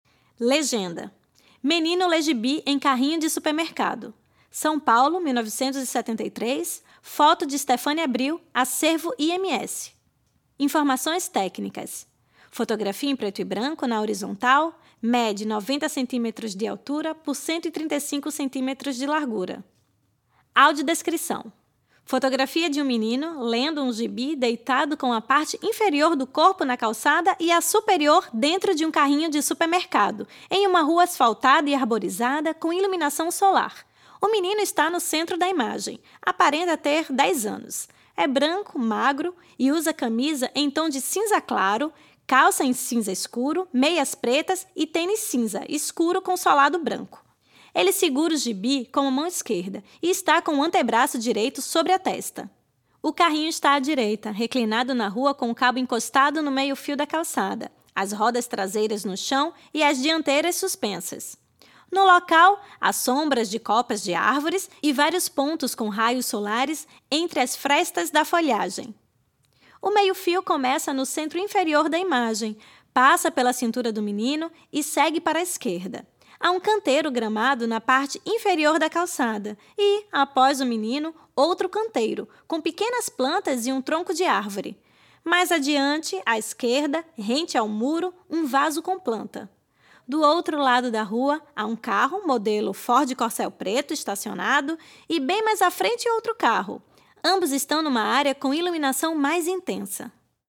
Faixa 1 - Audiodescrição